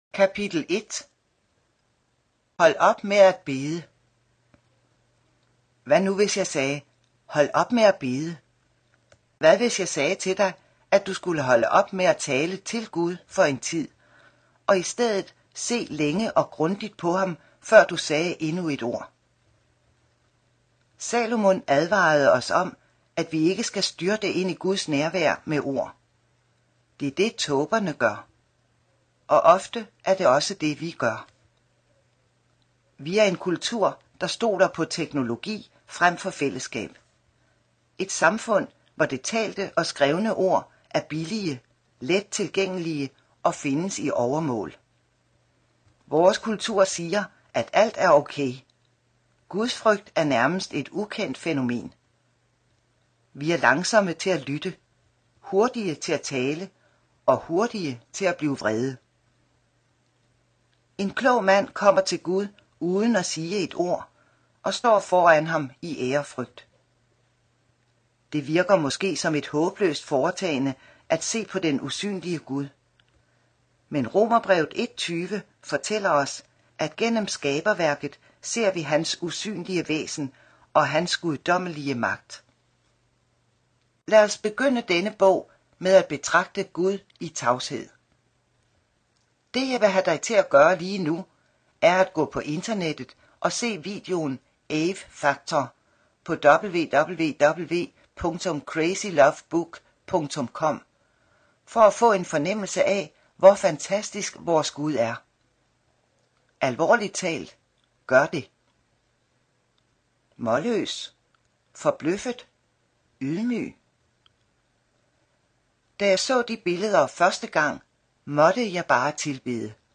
Lydprøve GRATIS - hør første kapitel